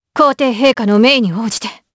Chrisha-Vox_Skill6_jp_b.wav